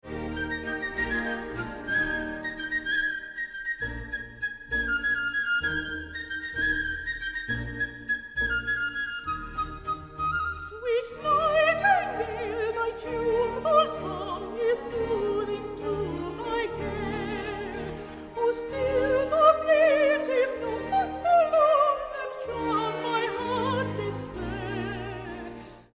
Vocal treasures of the 18th & 19th centuries
Soprano